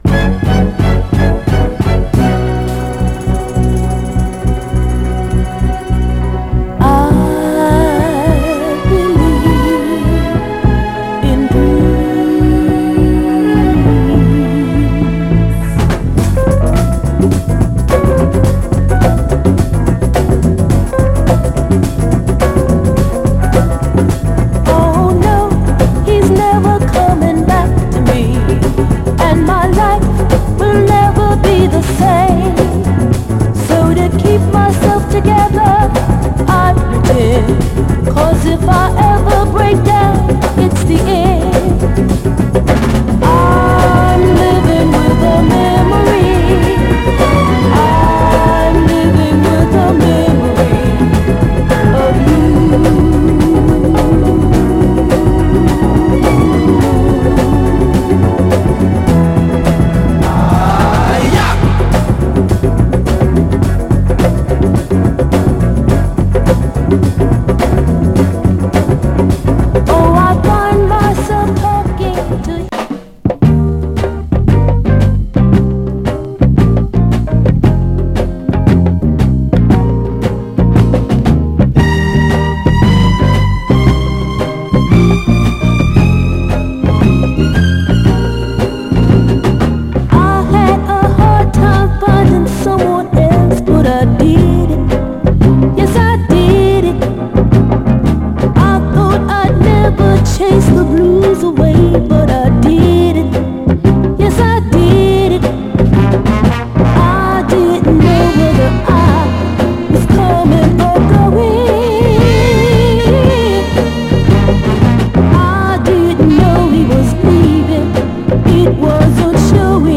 クールなエレピにパーカッシヴな疾走ブレイクビーツ・トラックがファンキーなのにメロウなクロスオーヴァー・ソウル
※試聴音源は実際にお送りする商品から録音したものです※